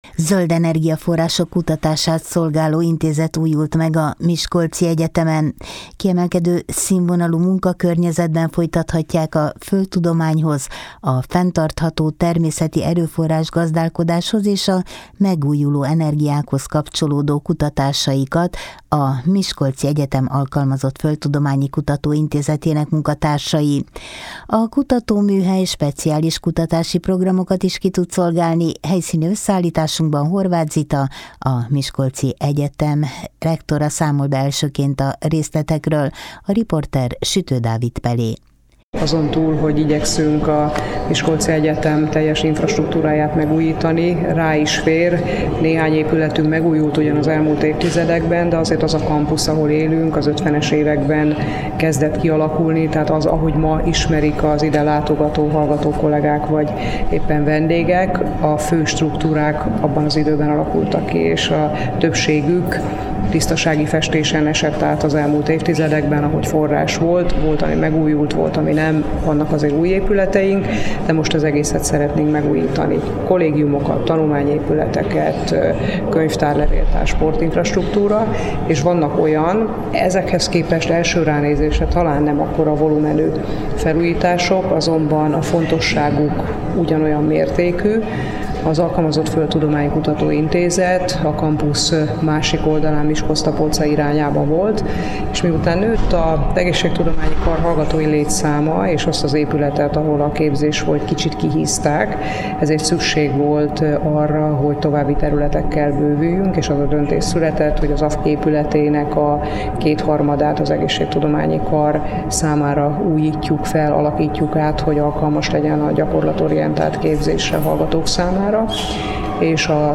Kiemelkedő színvonalú munkakörnyezetben folytathatják a földtudományhoz, a fenntartható természeti erőforrás-gazdálkodáshoz és a megújuló energiákhoz kapcsolódó kutatásaikat a Miskolci Egyetem Alkalmazott Földtudományi Kutatóintézetének munkatársai. A kutatóműhely speciális kutatási programokat is ki tud szolgálni. Helyszíni összeállításunkban